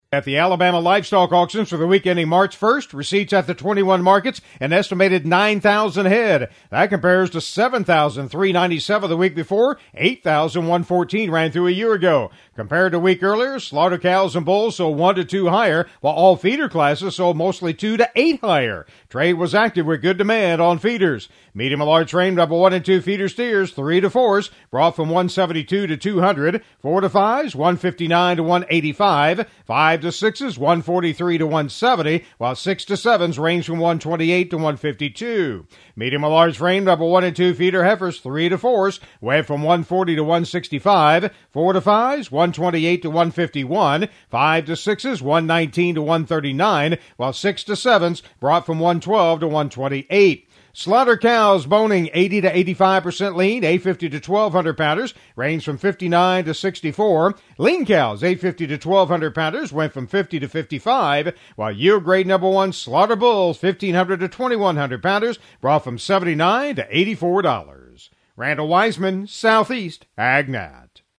AL Livestock Market Report: